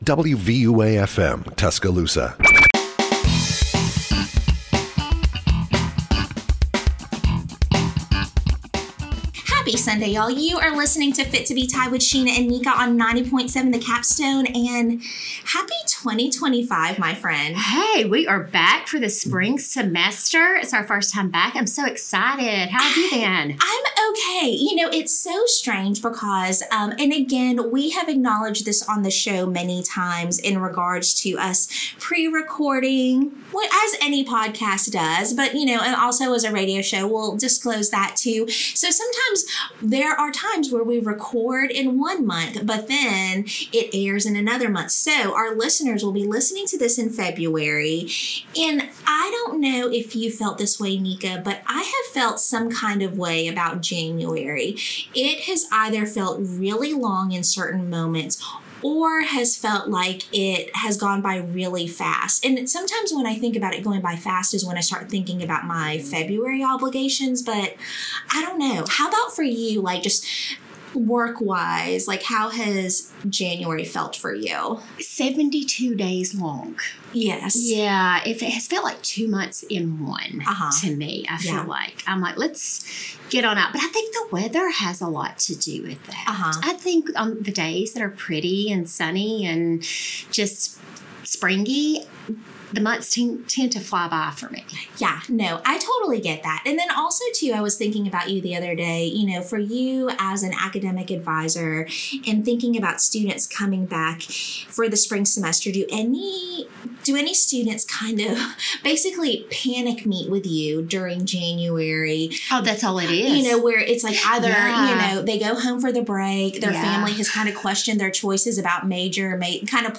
The conversation highlights that students can find fulfillment by participating in what they love while navigating their college experience.